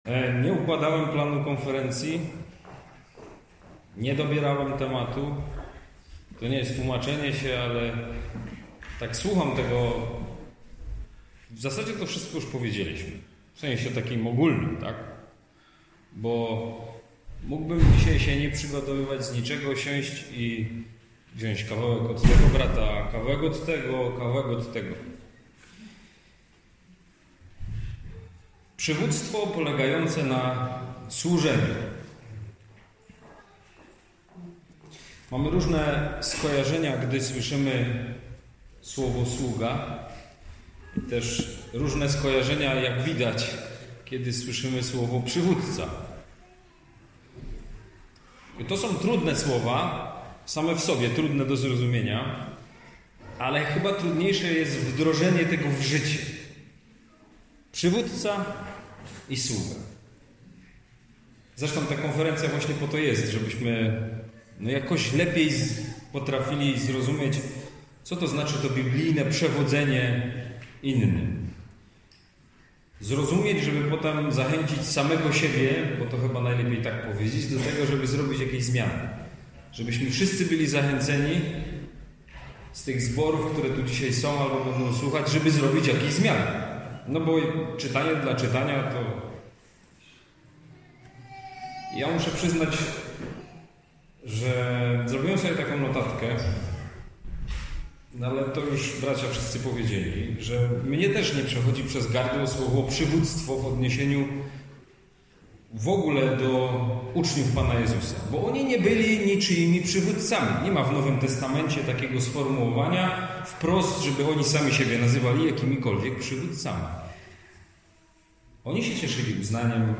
kazania
wygłoszonego podczas zjazdu w Jaworznie